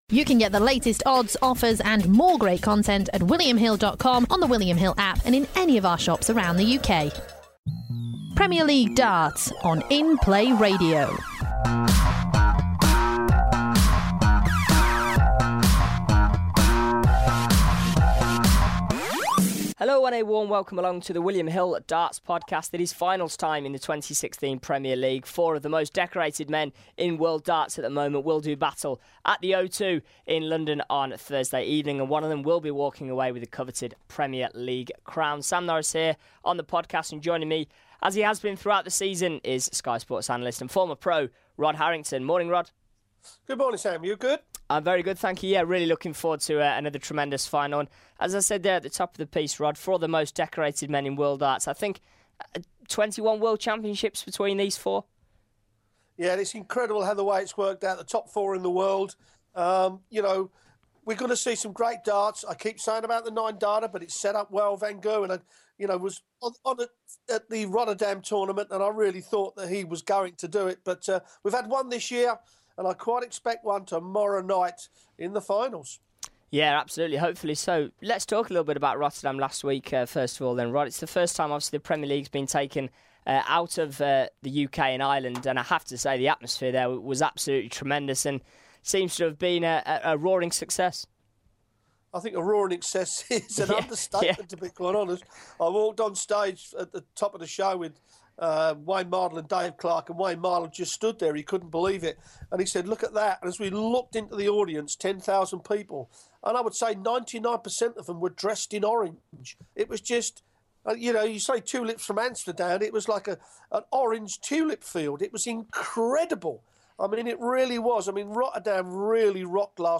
Former professional Darts player and Sky Sports pundit Rod Harrington